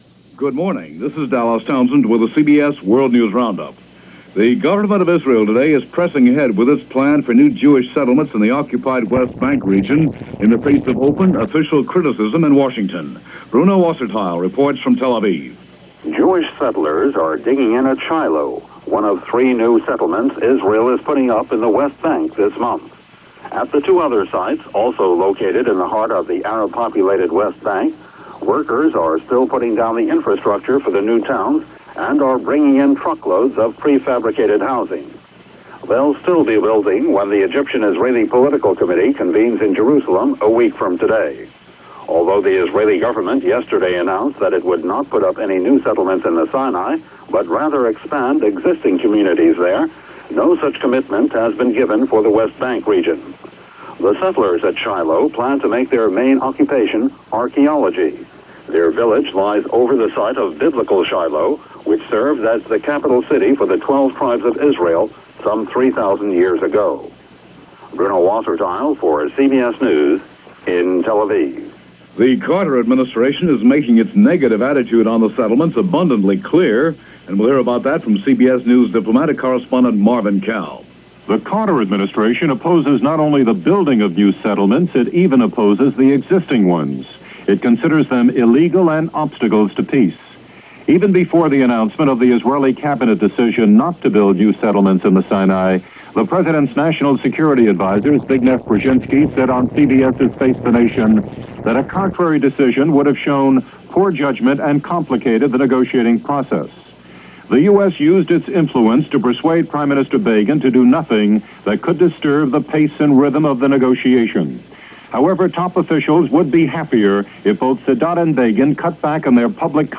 West Bank Settlements - OSHA Goes To Court - Vietnam-Cambodia War - January 9, 1978 - CBS World News Roundup.